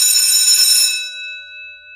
bell.ogg